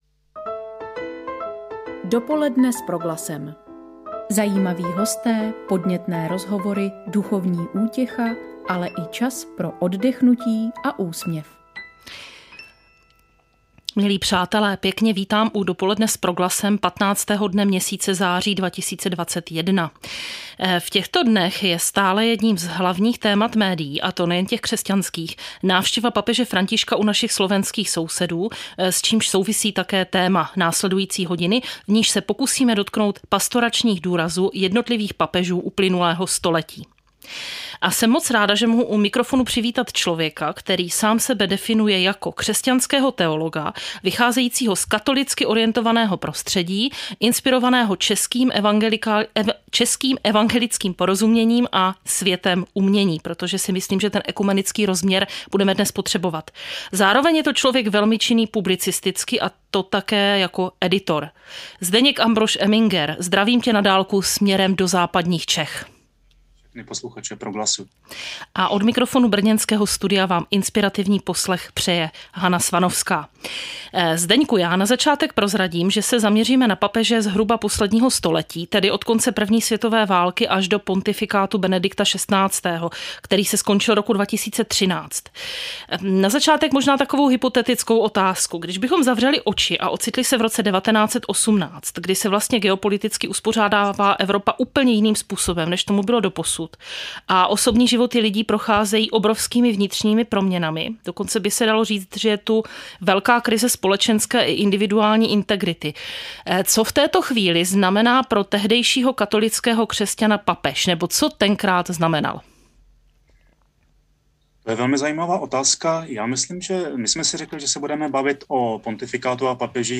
rozhovor s finským hudebníkem